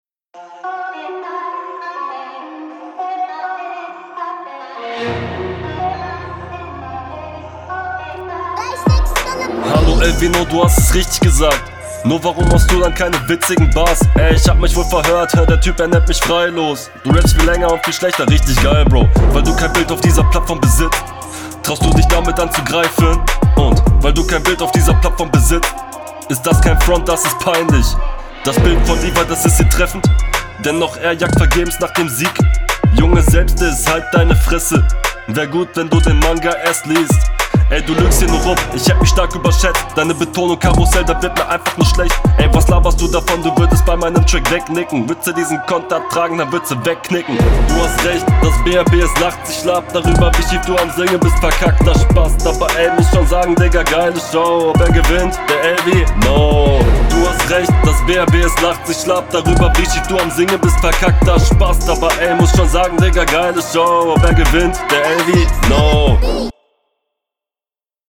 Diesmal stört mich am Flow etwas die Pausensetzung.